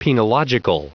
Prononciation du mot penological en anglais (fichier audio)
Prononciation du mot : penological